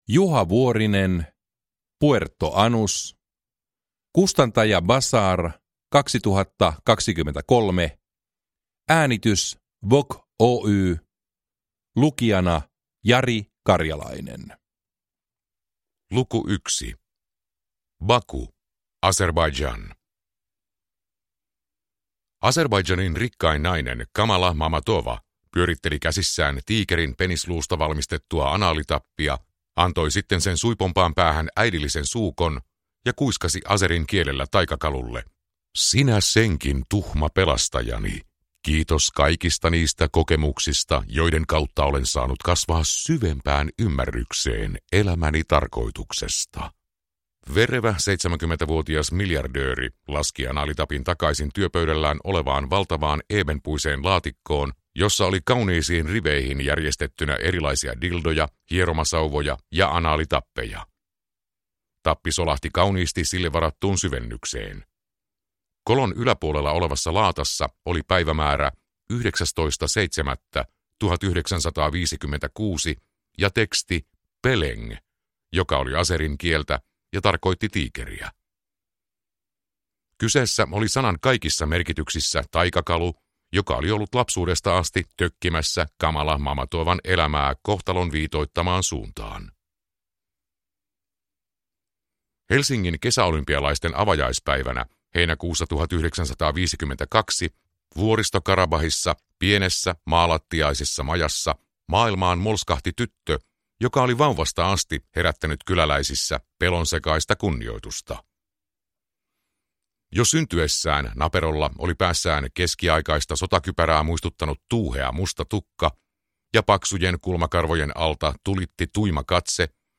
Puerto Anús – Ljudbok – Laddas ner